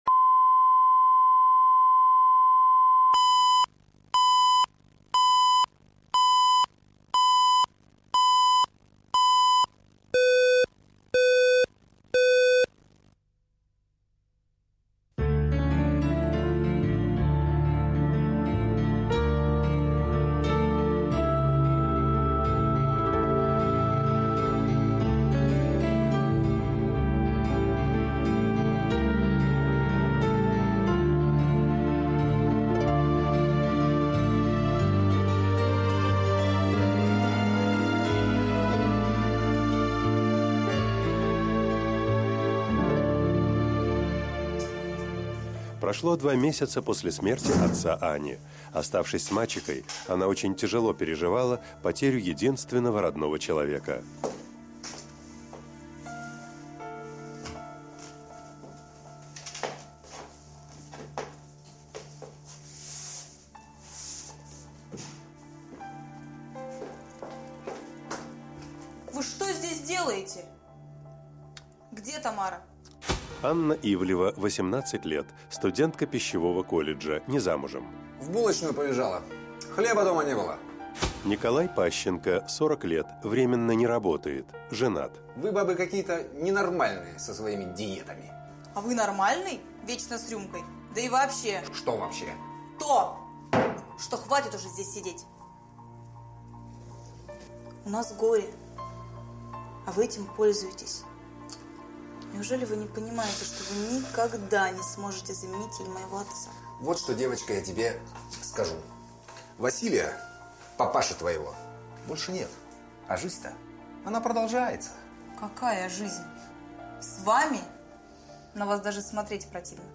Аудиокнига Дом без хозяина | Библиотека аудиокниг